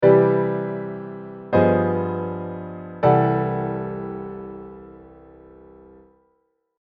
アッパー・ストラクチャー・トライアドさんは
極上のソース みたいなものですね。